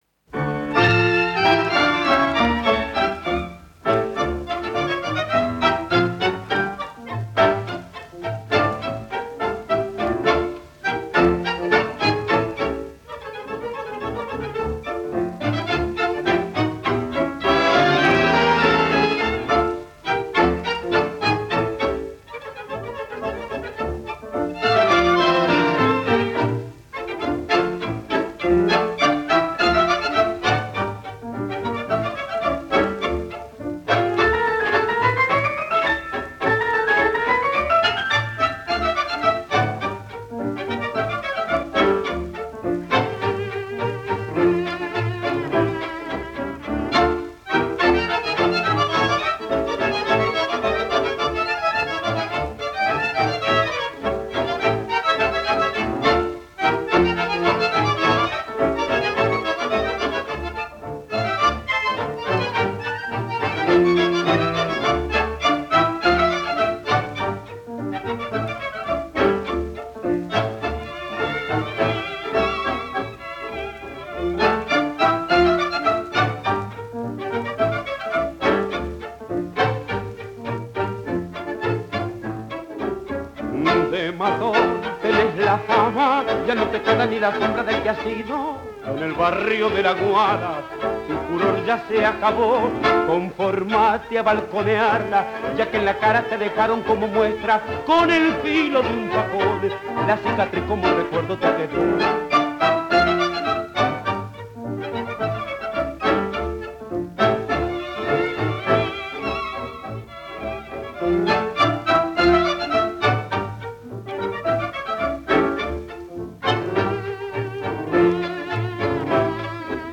Klavier
Eine Milonga mit Rhythmus und Charakter.